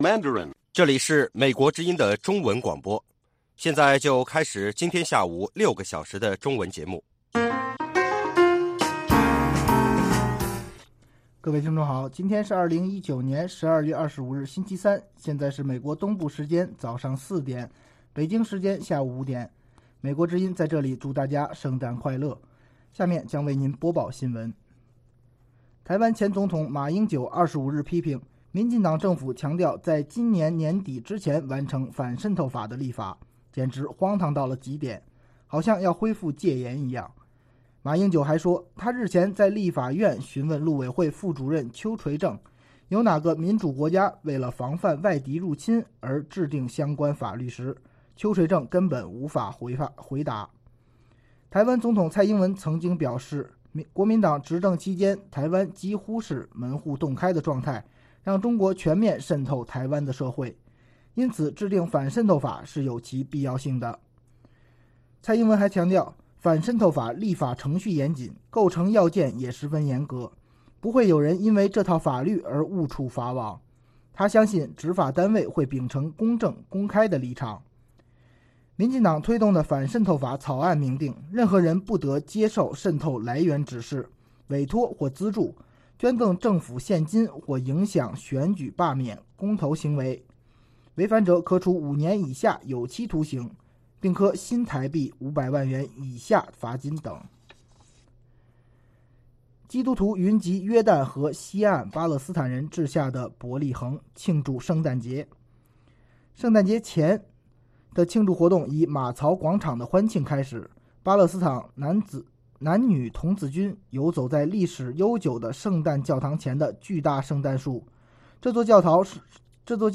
北京时间下午5-6点广播节目。广播内容包括国际新闻，收听英语，以及《时事大家谈》(重播)